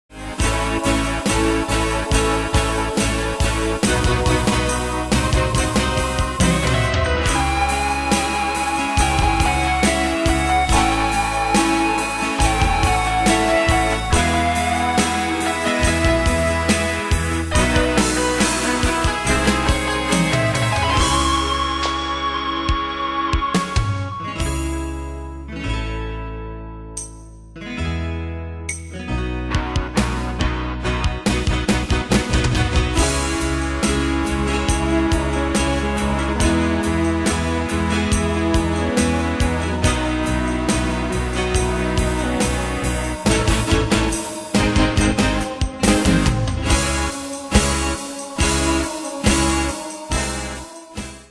Style: PopFranco Ane/Year: 2008 Tempo: 140 Durée/Time: 3.24
Danse/Dance: Ballade Cat Id.
Pro Backing Tracks